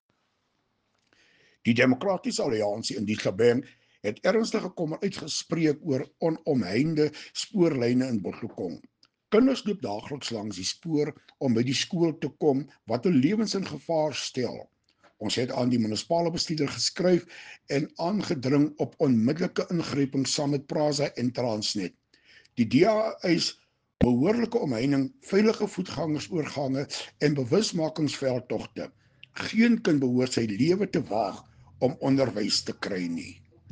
Afrikaans soundbite by Cllr Hilton Maasdorp.